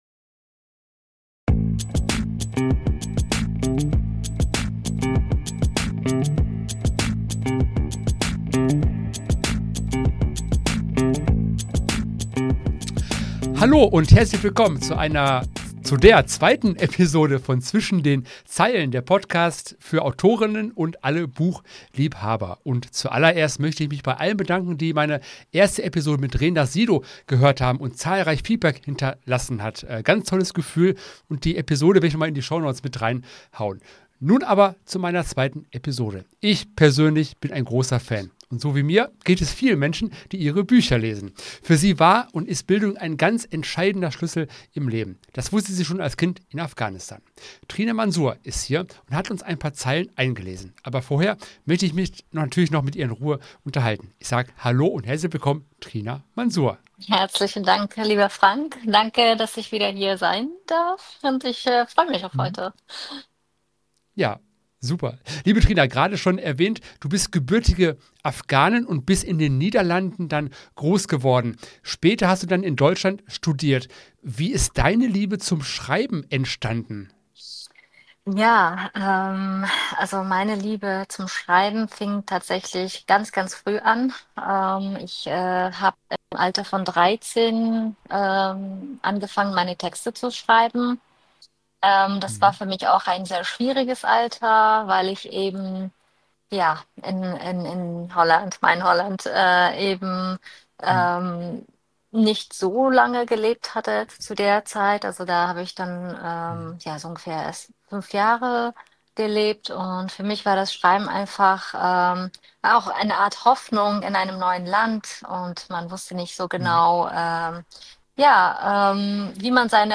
Gespräch mit Autorin